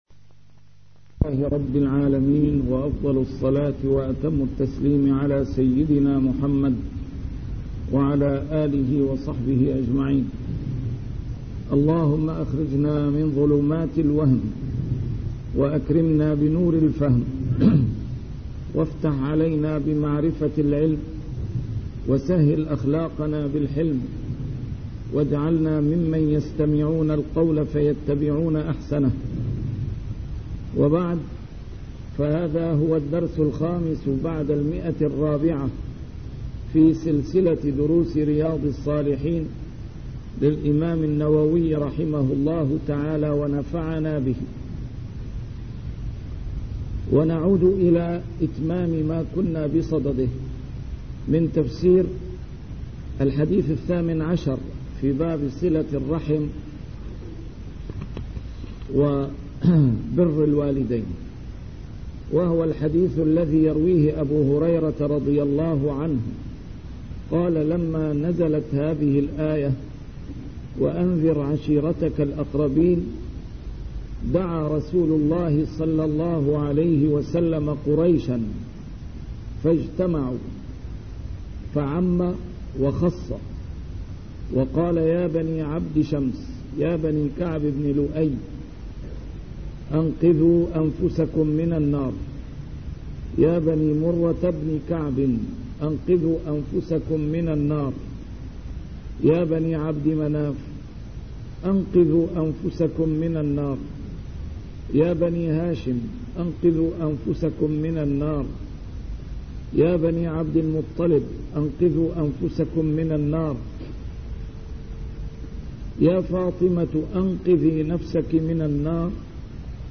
A MARTYR SCHOLAR: IMAM MUHAMMAD SAEED RAMADAN AL-BOUTI - الدروس العلمية - شرح كتاب رياض الصالحين - 405- شرح رياض الصالحين: بر الوالدين وصلة الأرحام